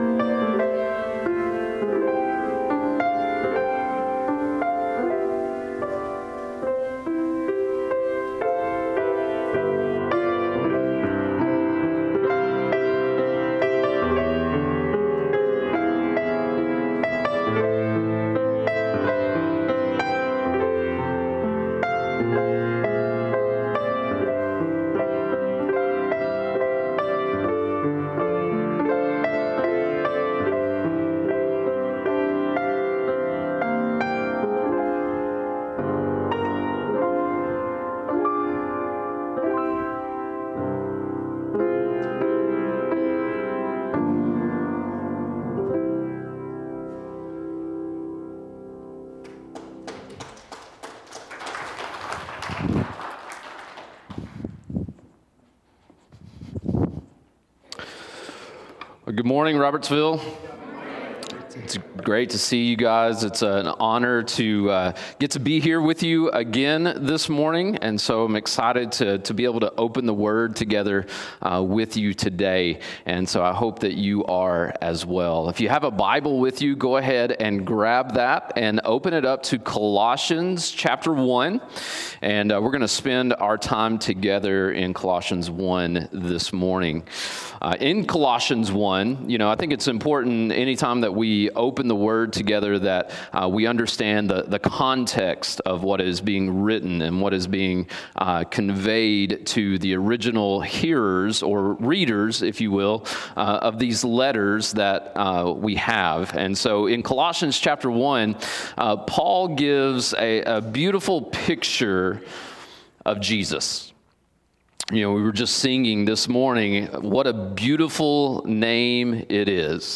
Sermons | Robertsville Baptist Church